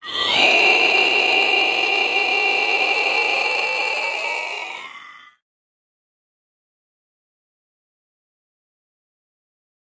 描述：同样，这是一根塑料汽水吸管在32盎司的塑料软饮料杯中被上下刮动的声音，我在追求哥斯拉/克洛弗菲尔德巨大的咆哮怪物的声音。低的声音是把吸管拉出来的，高的声音是把吸管推进去的。用罗技的USB麦克风录制，并通过Audacity运行，有gverb和音高变化。有些轨道（大约有4或5个）也是反转的。
标签： 恐怖 怪物 尖叫
声道立体声